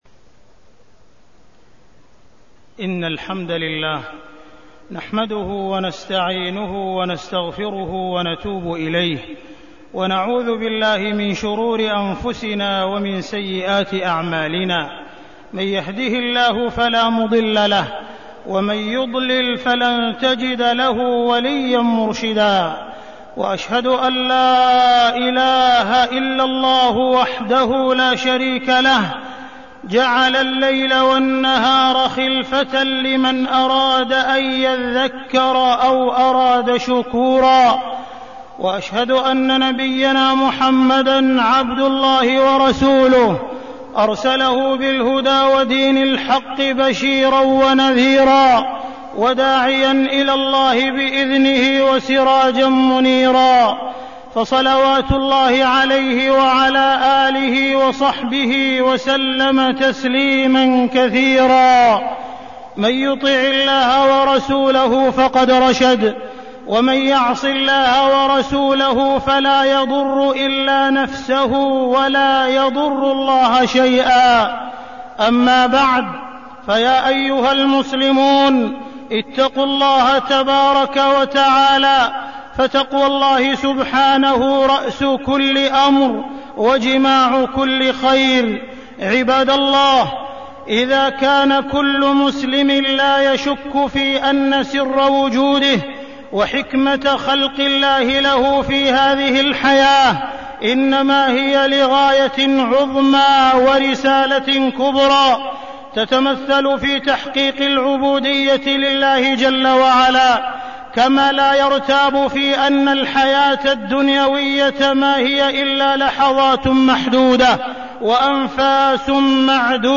تاريخ النشر ٢ ربيع الأول ١٤١٩ هـ المكان: المسجد الحرام الشيخ: معالي الشيخ أ.د. عبدالرحمن بن عبدالعزيز السديس معالي الشيخ أ.د. عبدالرحمن بن عبدالعزيز السديس الوقت في حياتنا